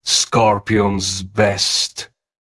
ok, Now its voice content has also been completed